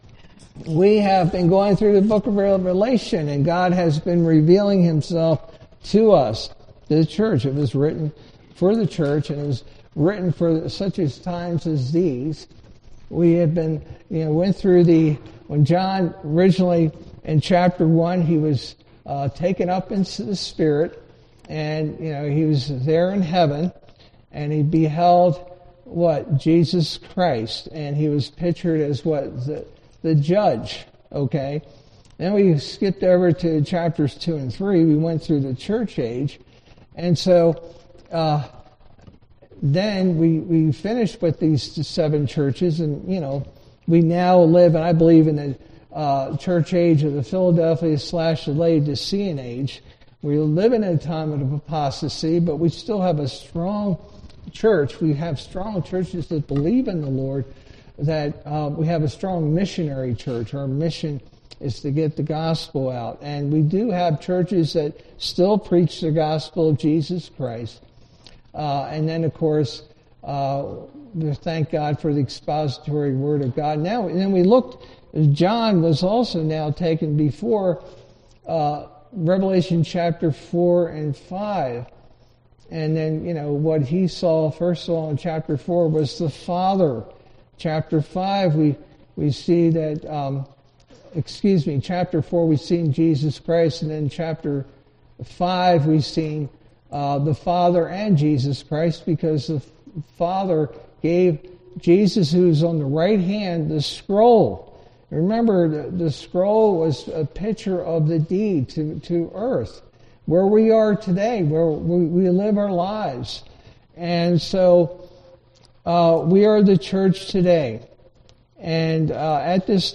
All Sermons The First Four Seals 15 March 2026 Series